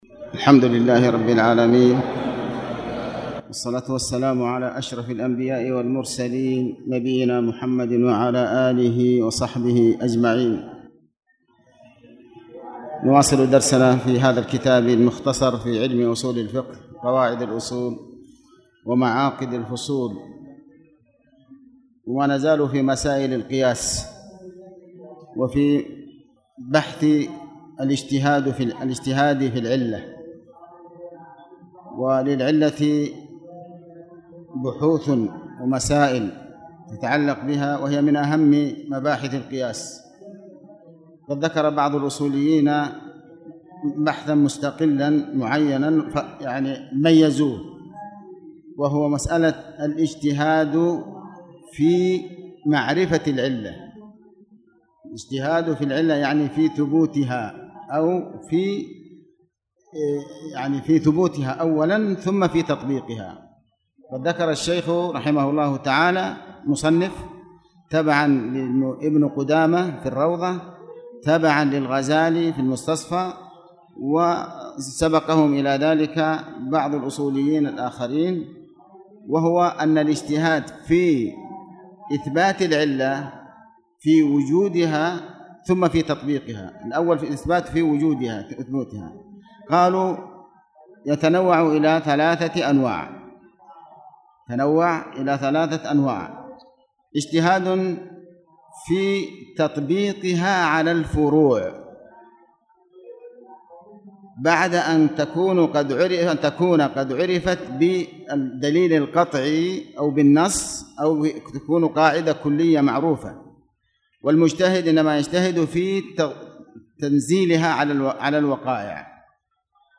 تاريخ النشر ١ ربيع الأول ١٤٣٨ هـ المكان: المسجد الحرام الشيخ